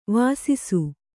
♪ vāsisu